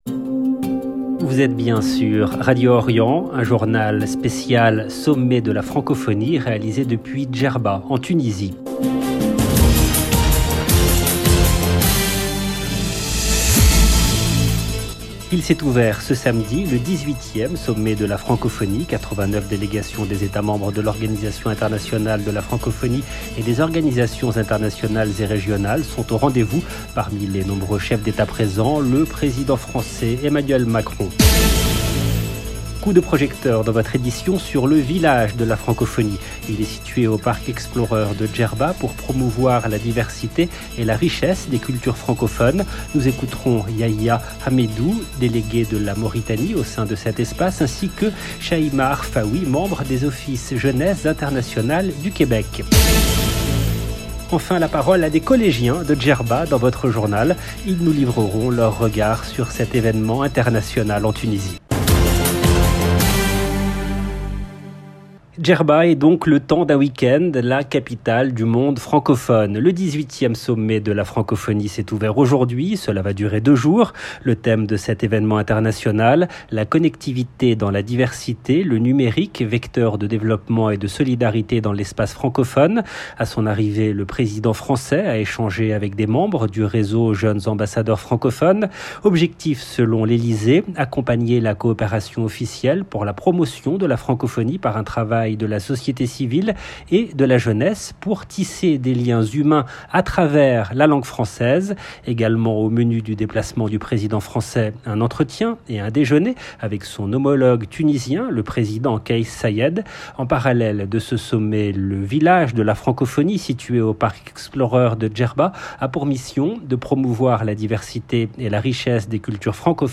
FRANCOPHONIE TUNISIE DJERBA 19 novembre 2022 - 12 min 30 sec LE JOURNAL DE 12 H EN LANGUE FRANCAISE DU 19/11/2022 JS EDITION SPECIALE DJERBA FRANCOPHONIE Un journal spécial Sommet de la Francophonie réalisé depuis Djerba en Tunisie. Il s’est ouvert ce samedi, le 18e sommet de la Francophonie. 89 délégations des États membres de l'Organisation internationale de la Francophonie et des organisations internationales et régionales sont au rendez-vous.
Enfin la parole à des collégiens de Djerba dans votre journal.